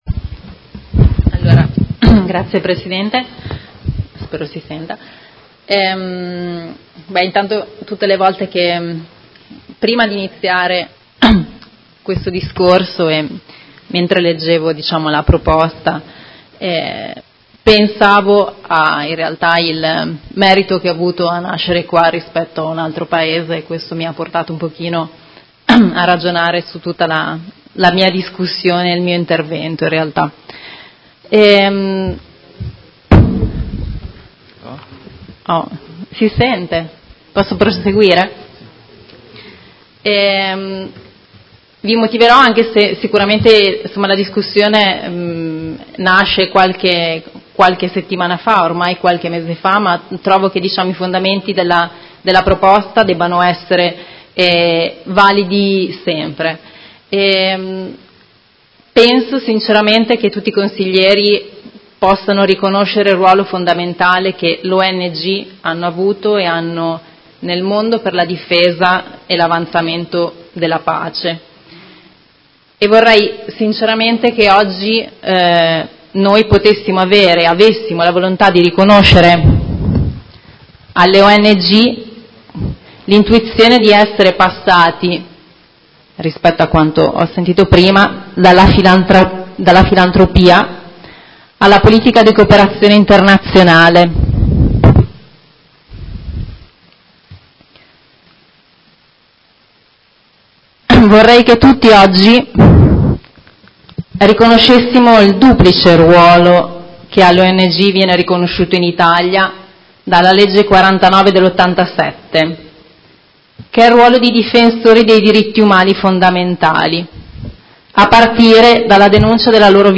Ilaria Franchini — Sito Audio Consiglio Comunale